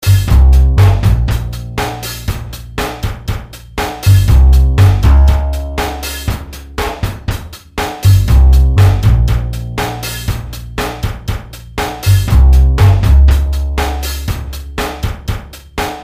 描述：真正的鼓声 真正的立式贝司音色
标签： 120 bpm Drum And Bass Loops Groove Loops 2.69 MB wav Key : Unknown
声道立体声